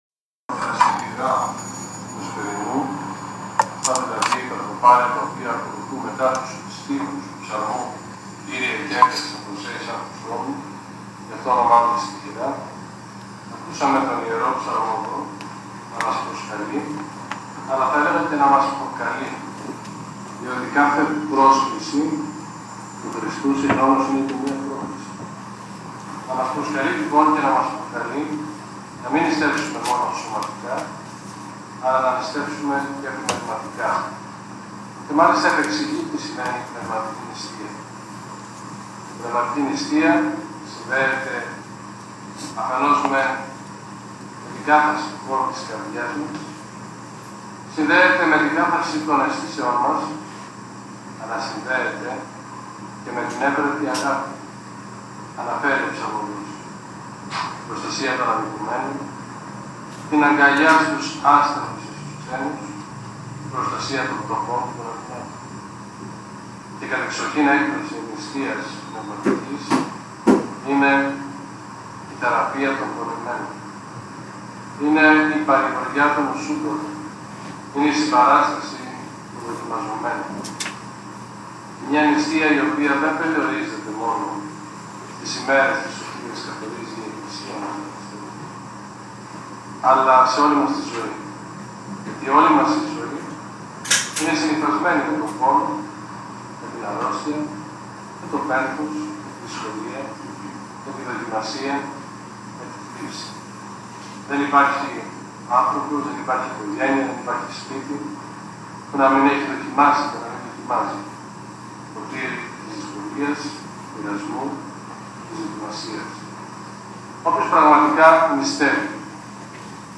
Ακούστε την ομιλία του Θεοφιλεστάτου